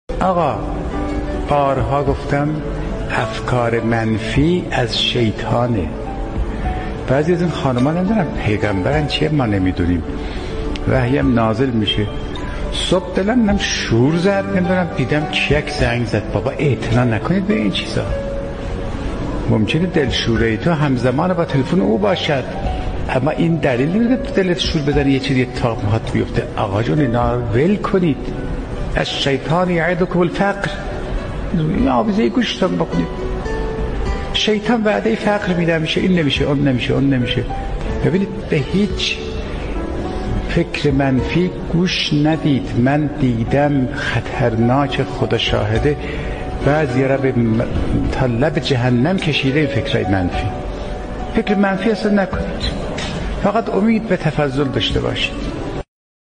عقیق: مرحوم آیت الله فاطمی نیا در یکی از سخنرانی های خود به موضوع «رهایی از افکار پریشان و وسوسه های شیطانی» اشاره کرد که تقدیم شما فرهیختگان می شود.